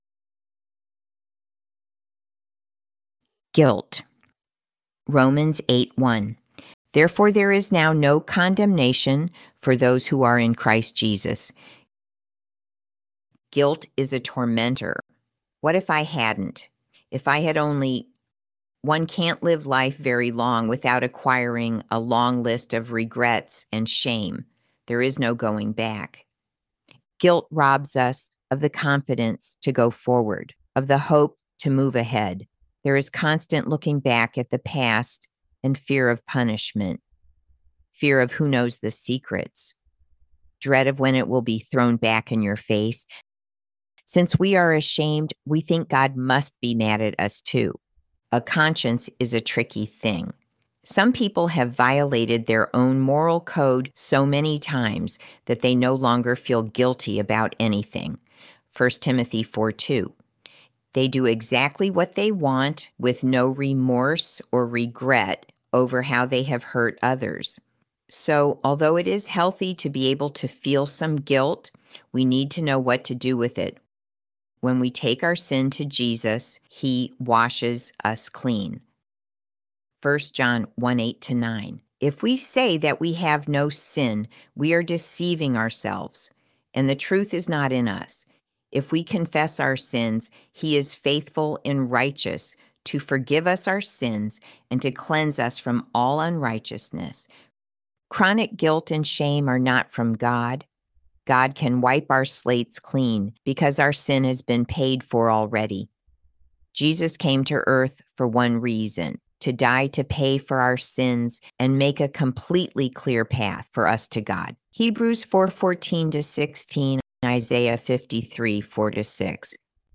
I used an AI clone of my voice to create this audio book.